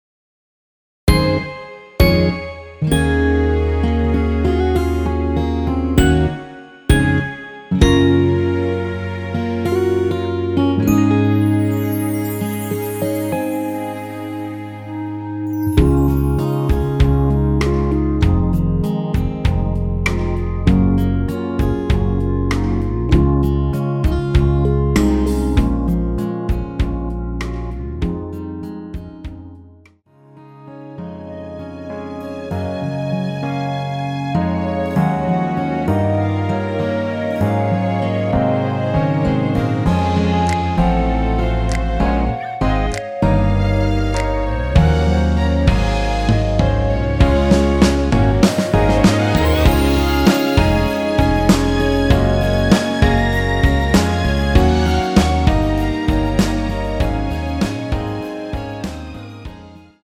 원키에서(-1)내린 멜로디 포함된 MR입니다.
Db
앞부분30초, 뒷부분30초씩 편집해서 올려 드리고 있습니다.
중간에 음이 끈어지고 다시 나오는 이유는